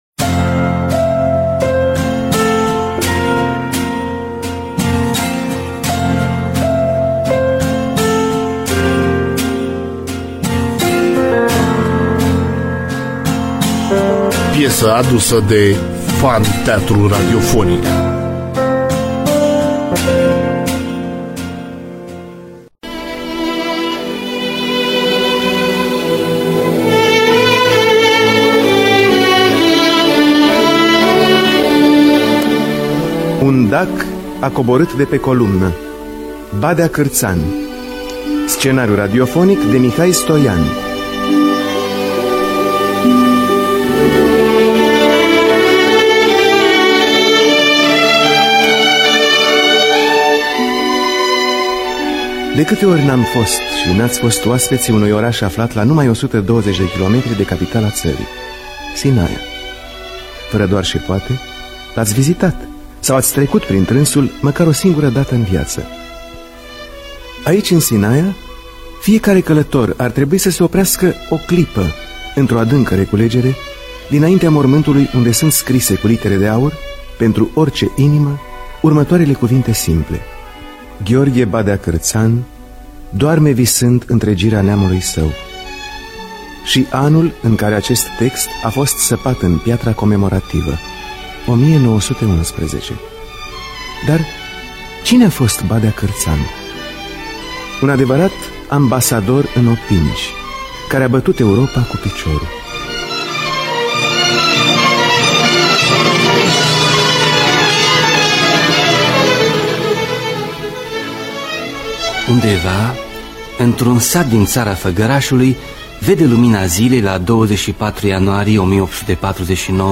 Biografii, Memorii: Badea Cartan (1978) – Teatru Radiofonic Online